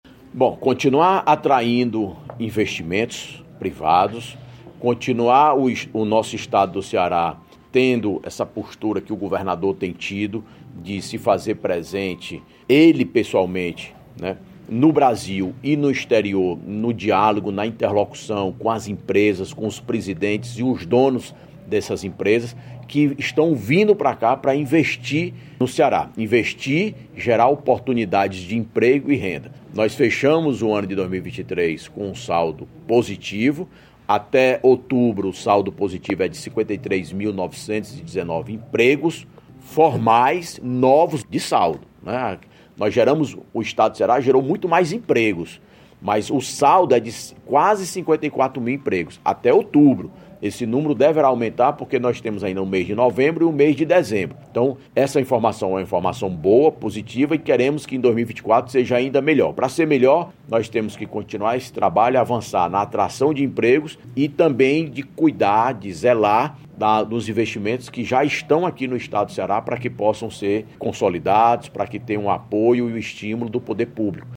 Em entrevista, Salmito Filho discutiu não apenas os avanços presentes, mas também os desafios futuros. Ele ressaltou o objetivo para 2024 de manter os investimentos públicos e atrair capital privado adicional.